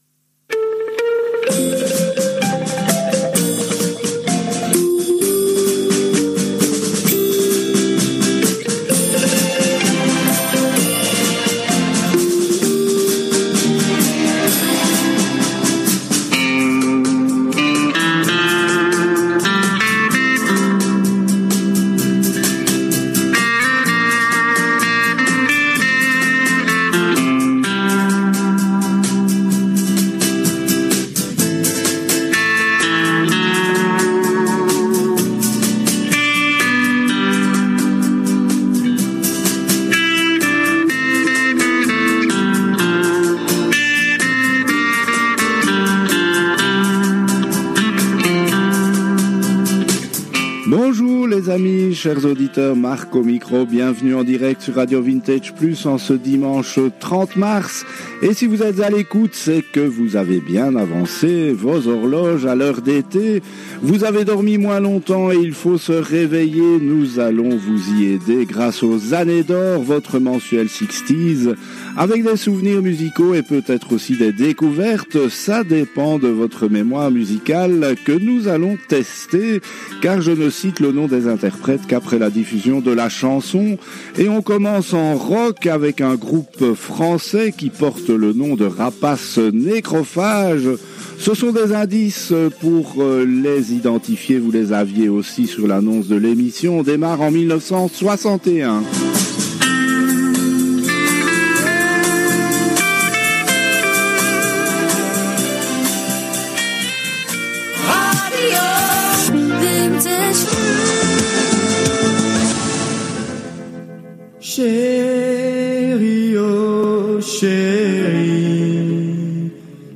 Les Années d’Or sur RADIO VINTAGE PLUS, c’est une émission mensuelle entièrement consacrée aux SIXTIES.